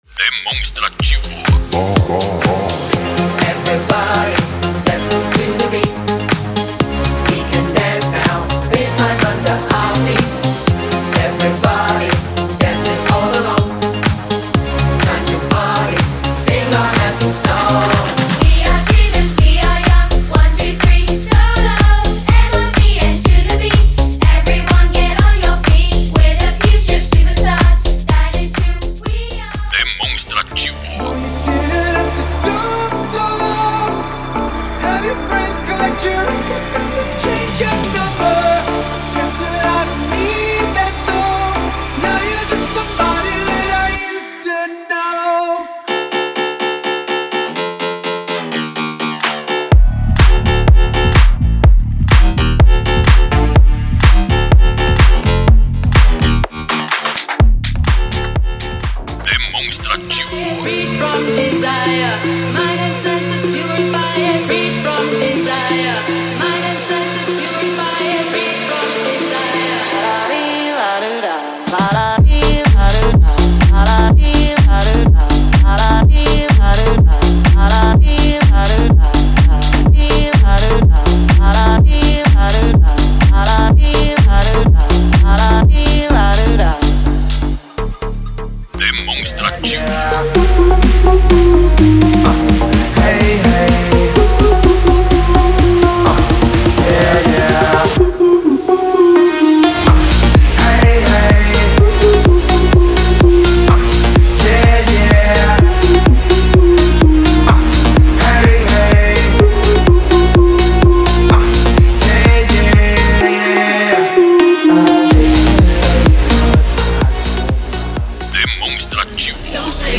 Eletrônica / Funk / Sertanejo e outros.